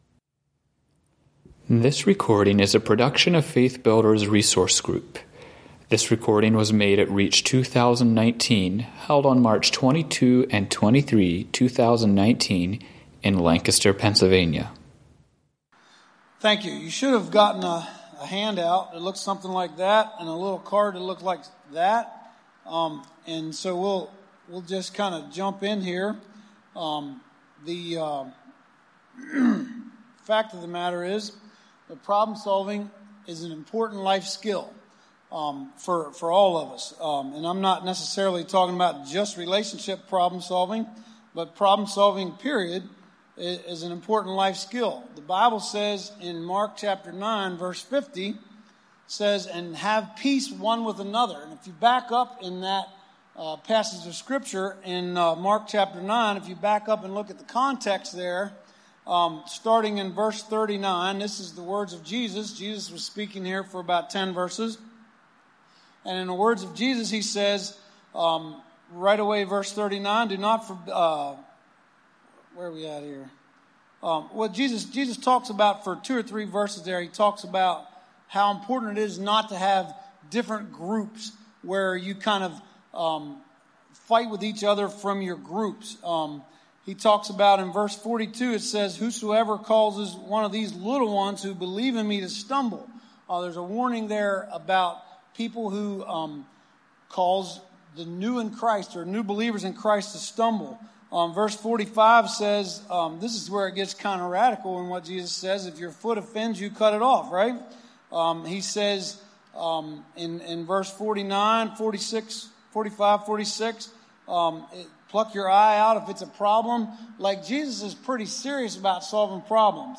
Home » Lectures » Relationship Problem Solving